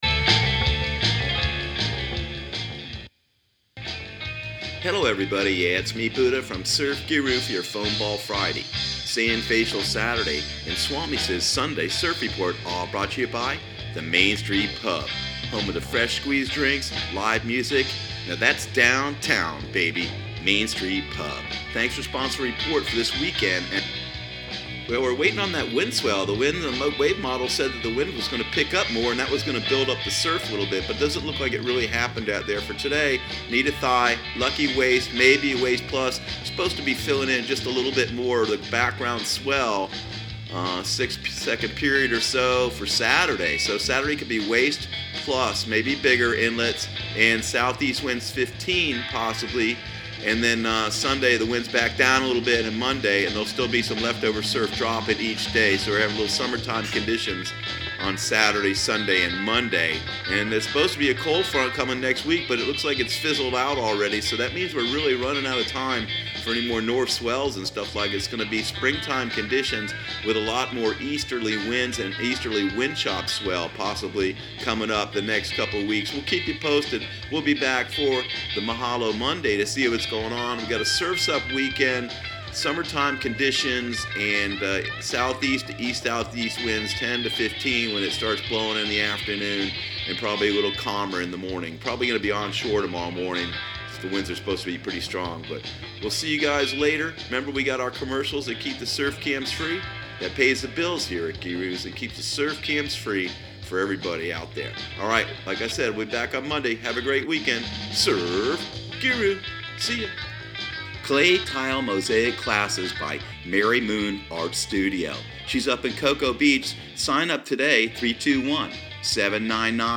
Surf Guru Surf Report and Forecast 03/08/2019 Audio surf report and surf forecast on March 08 for Central Florida and the Southeast.